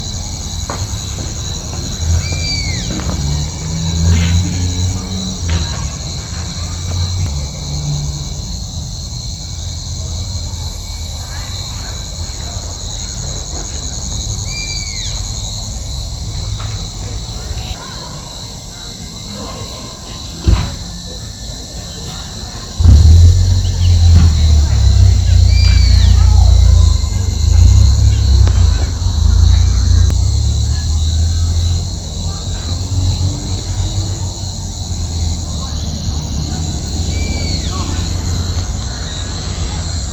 Lechuzón Orejudo (Asio clamator)
Localidad o área protegida: Concepción del Yaguareté Corá
Condición: Silvestre
Certeza: Observada, Vocalización Grabada
Lechuzon-orejudo.mp3